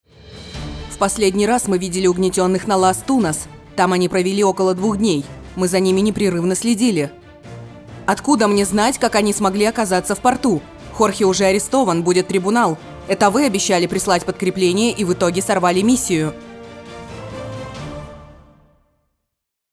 Диктор
ЗАКАДР